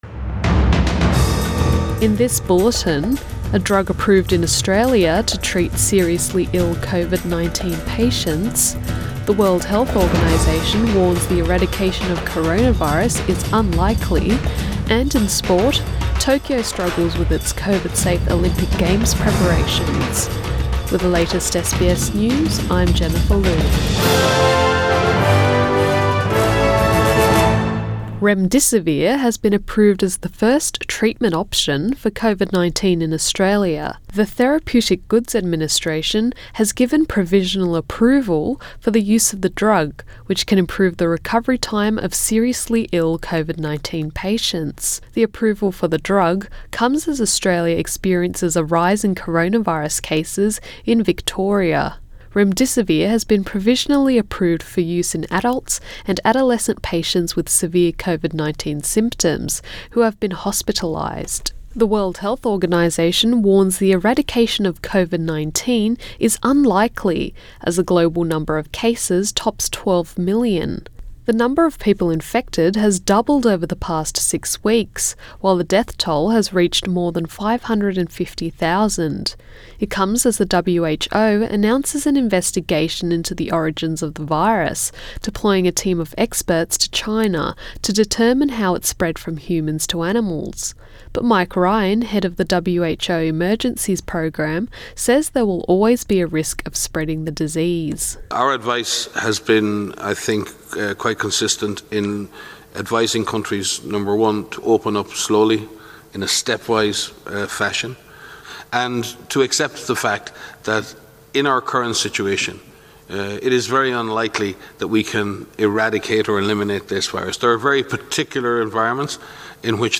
AM bulletin 11 July 2020